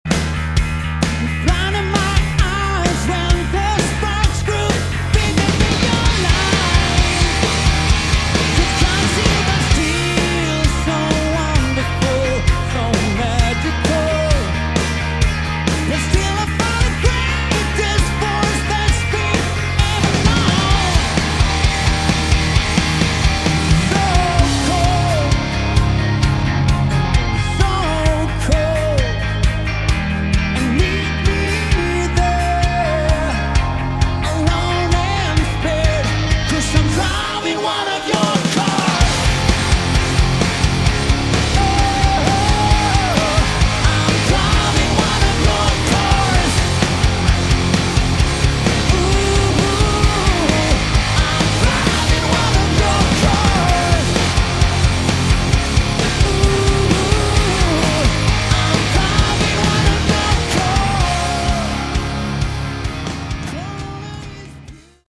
Category: Hard Rock
Quarantine Live